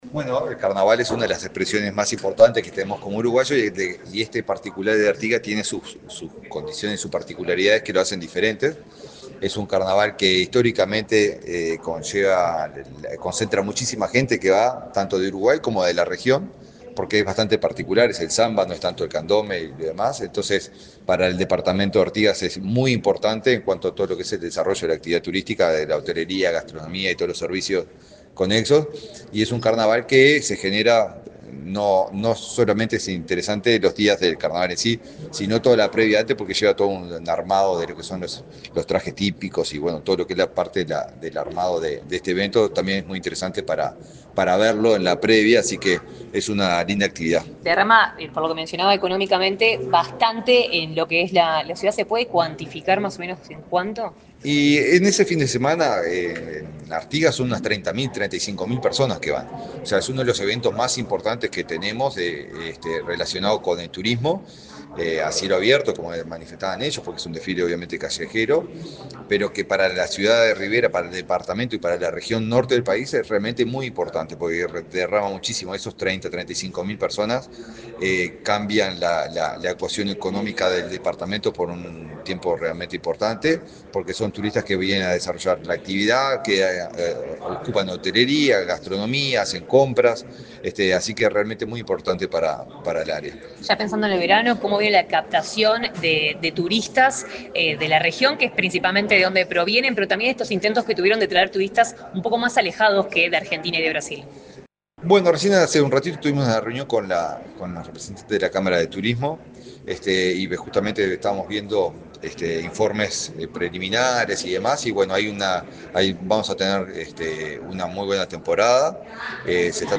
Declaraciones del ministro de Turismo, Eduardo Sanguinetti
Este miércoles 4 en Montevideo, el ministro de Turismo, Eduardo Sanguinetti, dialogó con la prensa, luego de participar en el lanzamiento del carnaval